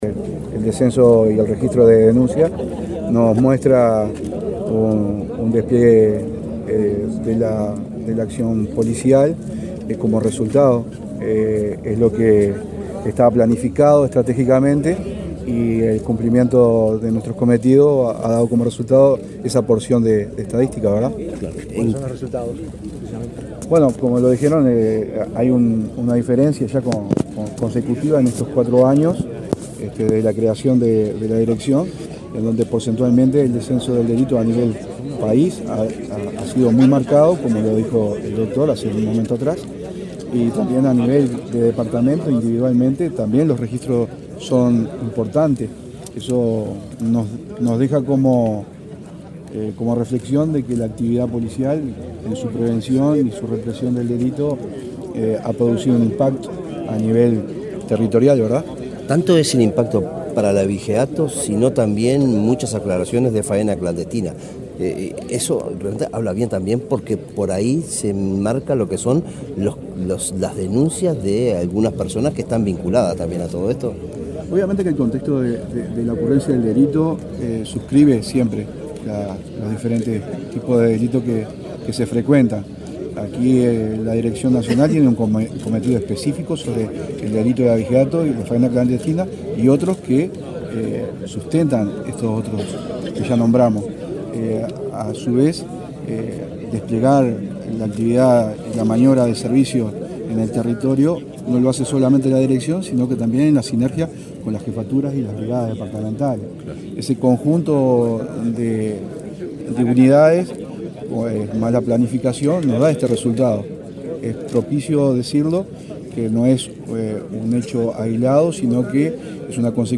Declaraciones del director nacional de Seguridad Rural, Ernesto Cossio
El director nacional de Seguridad Rural, Ernesto Cossio, dialogó con la prensa en Florida, donde se celebró el cuarto aniversario de esa repartición